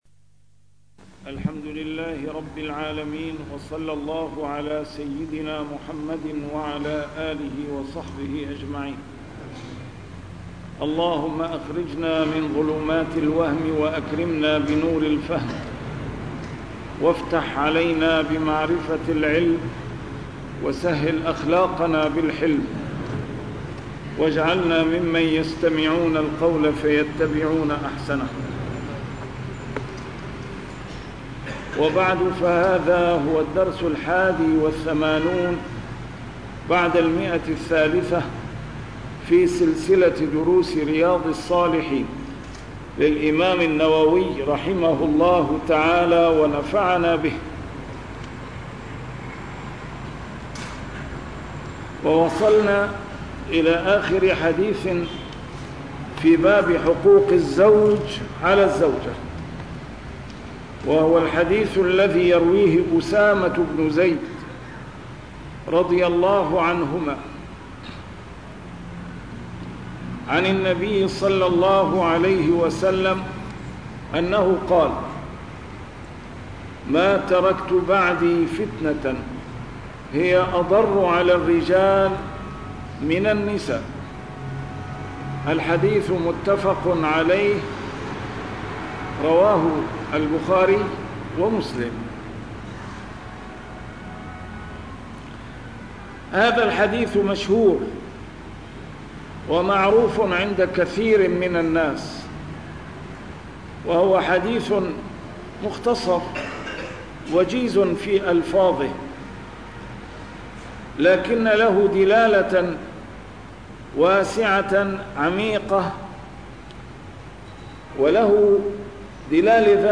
شرح كتاب رياض الصالحين - A MARTYR SCHOLAR: IMAM MUHAMMAD SAEED RAMADAN AL-BOUTI - الدروس العلمية - علوم الحديث الشريف - 381- شرح رياض الصالحين: حق الزوج على المرأة